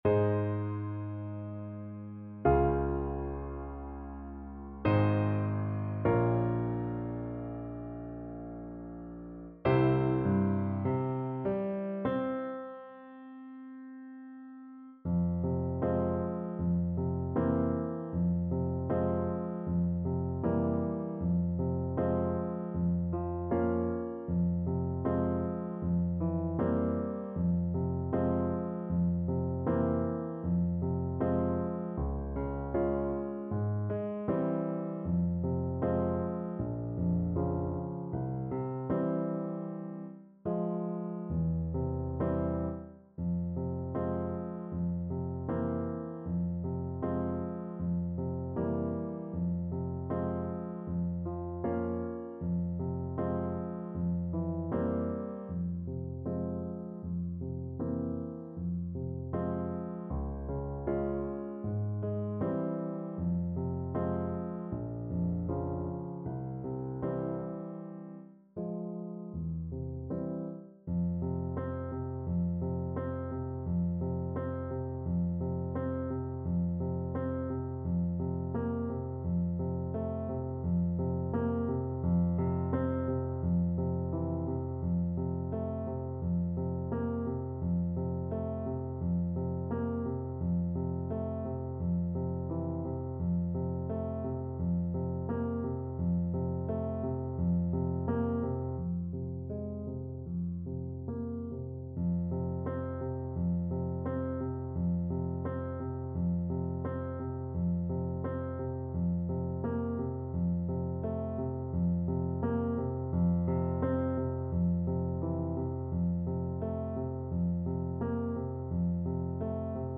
Ab major (Sounding Pitch) Bb major (Clarinet in Bb) (View more Ab major Music for Clarinet )
Largo
4/4 (View more 4/4 Music)